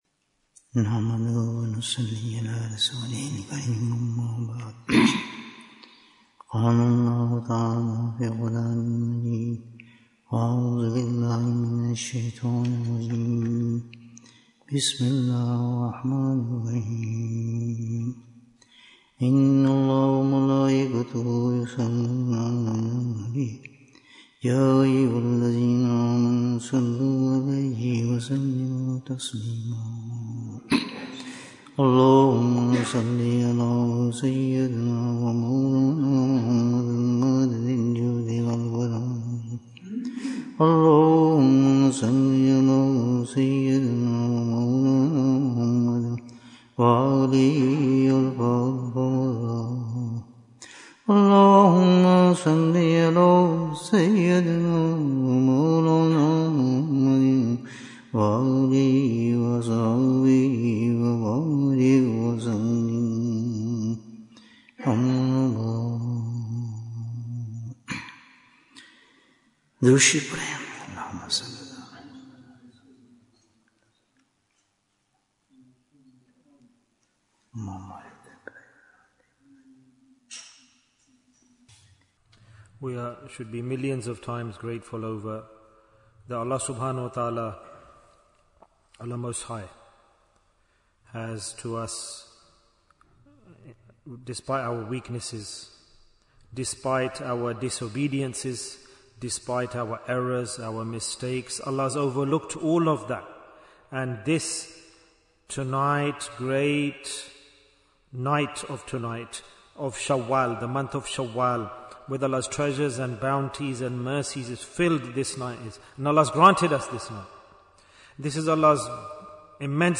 How Can We Save Our Iman? Bayan, 75 minutes17th April, 2025